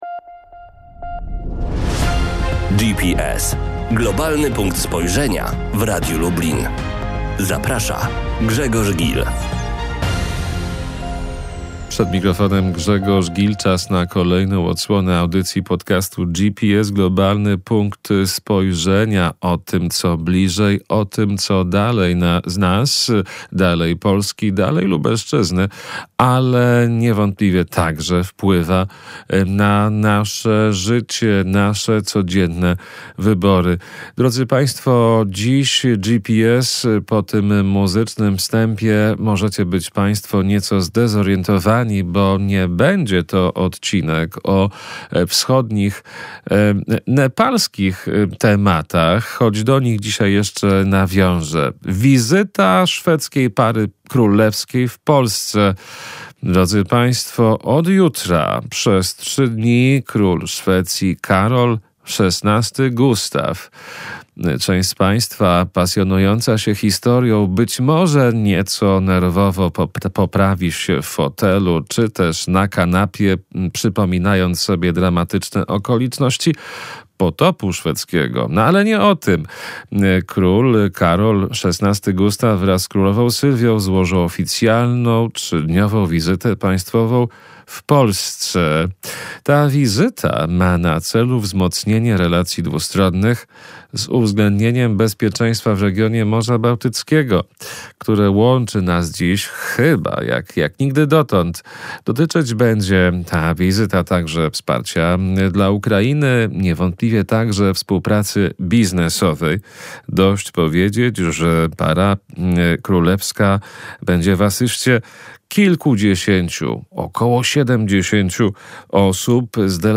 niezależnym ekspertem ze Sztokholmu